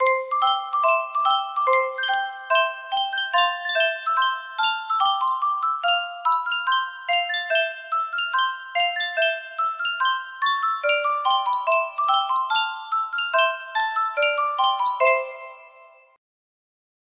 Sankyo Music Box - Here We Come A-Caroling URW music box melody
Full range 60